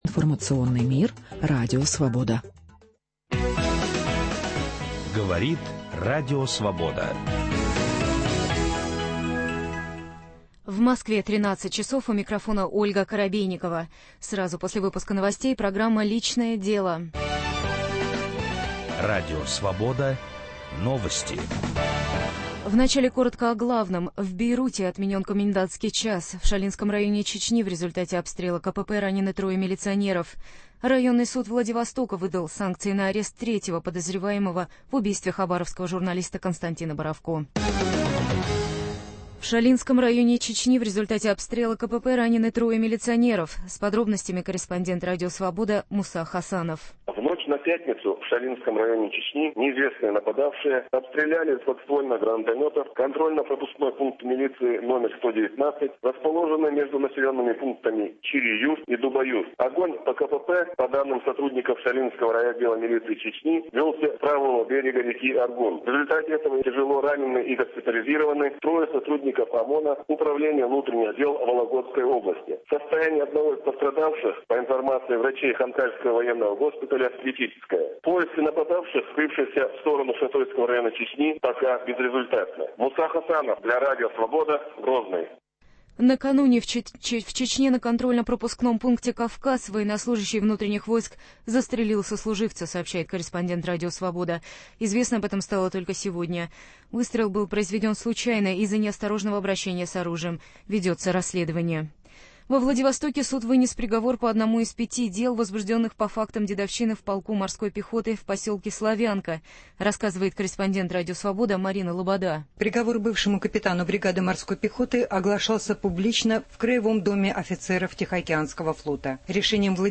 Может ли любовь унижать? В прямом эфире